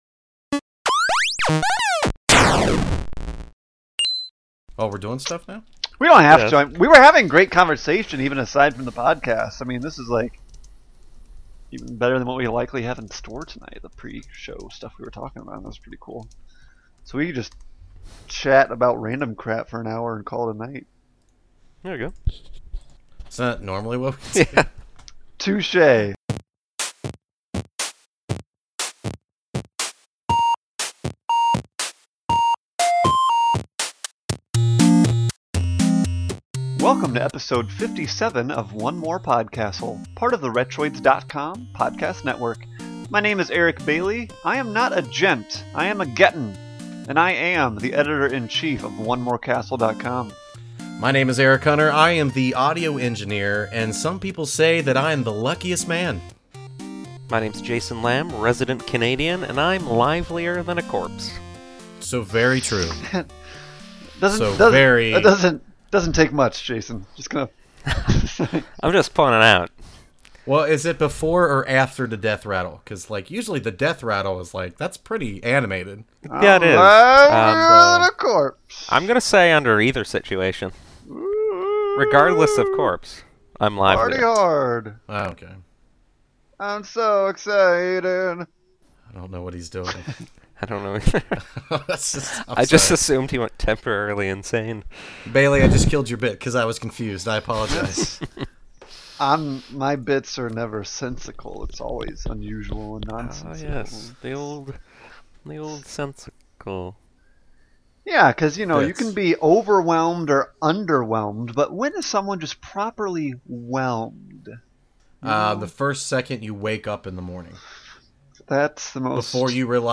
Watch the live recording: Play the audio episode: /